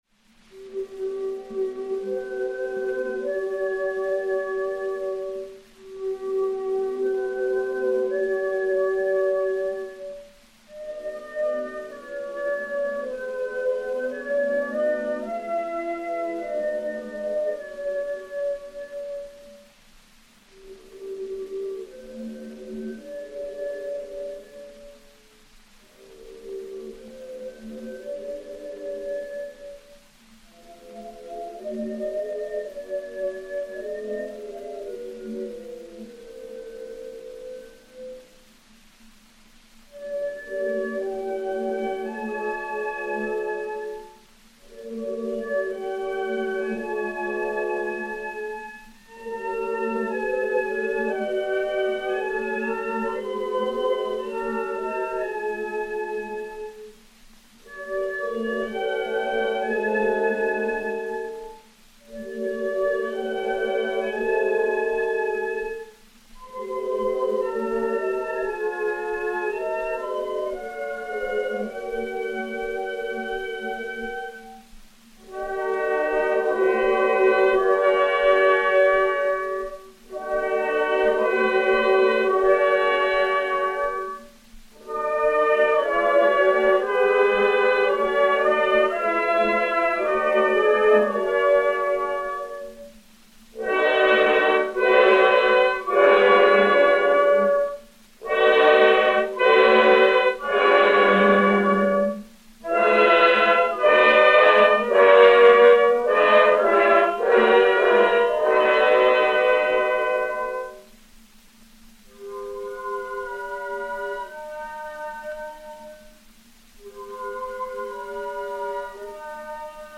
Orchestre dir.
Pathé saphir 90 tours n° 7147-1, enr. à Paris vers 1912